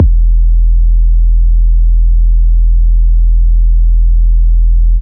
E-EDMBass-4.wav